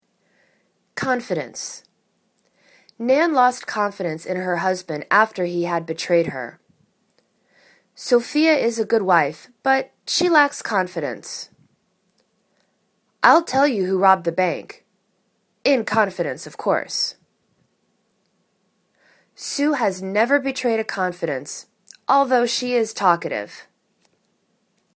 con.fi.dence  /'konfidәns/ n